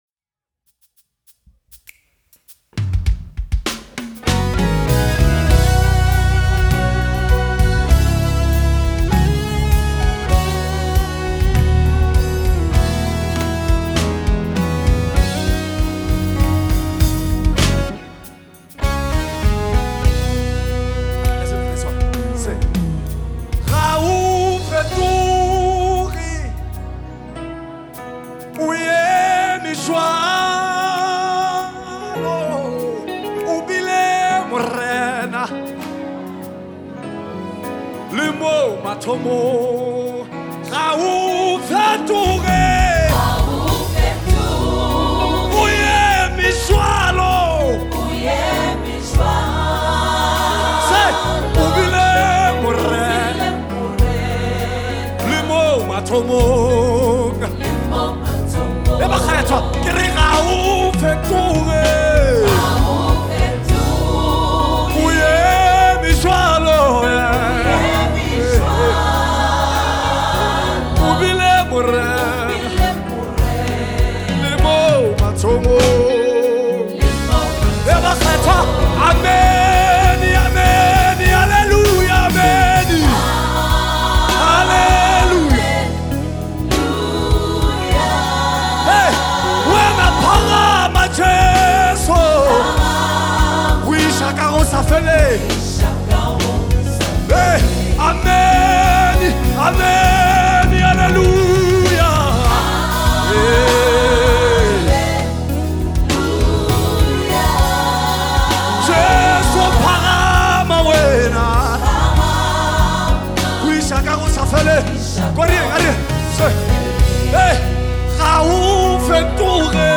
GENRE: South African Gospel.